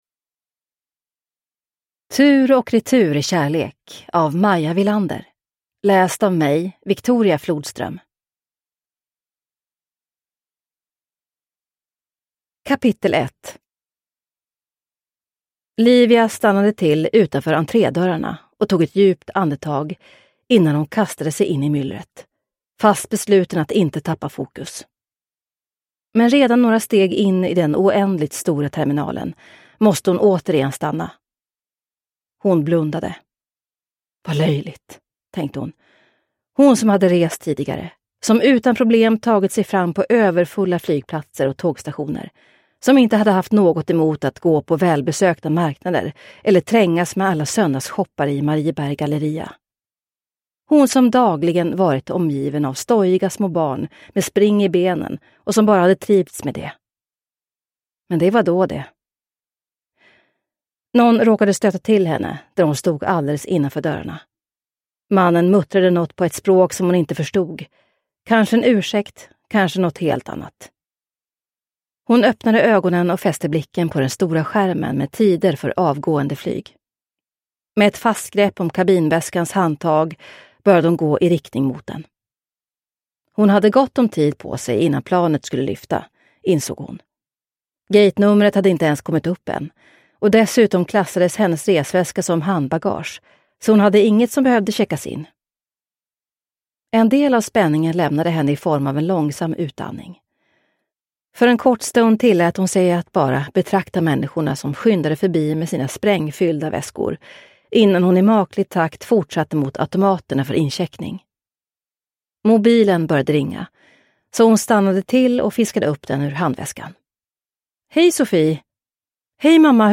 Tur och retur i kärlek – Ljudbok – Laddas ner